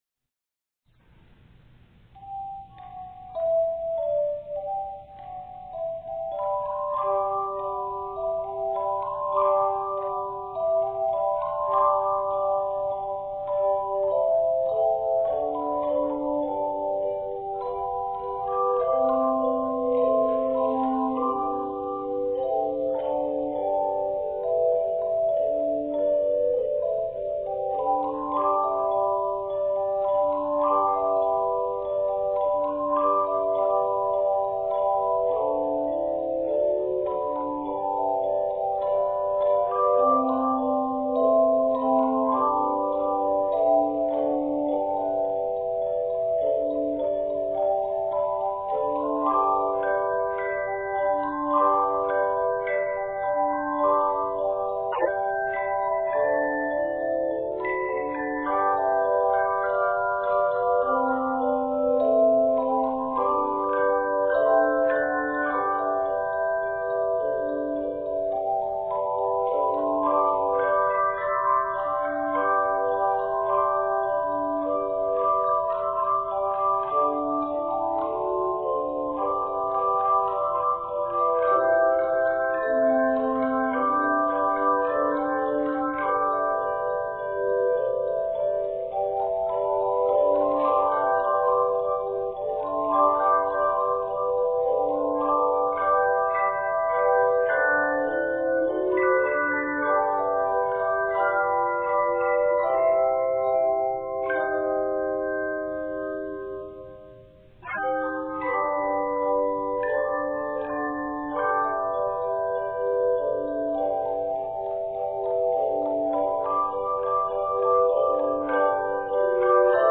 folk and patriotic arrangements
Few techniques make the pieces accessible for chimes.